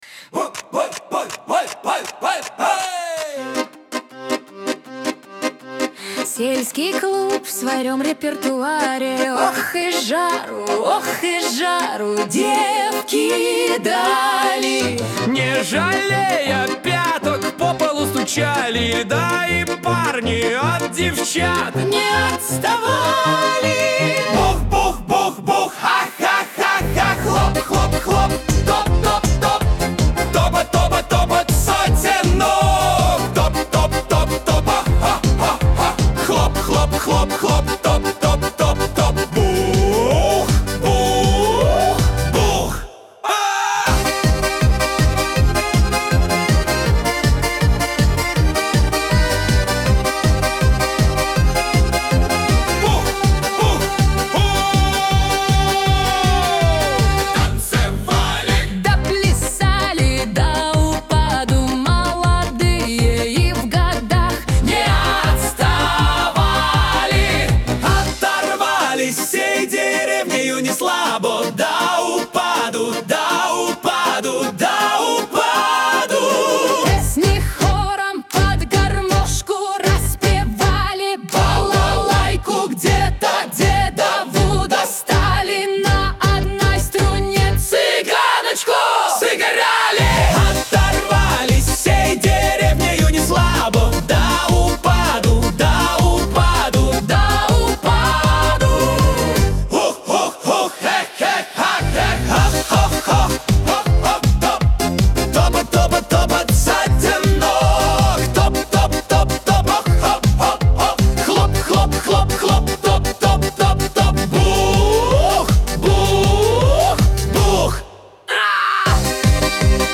pop , дуэт , dance , эстрада
диско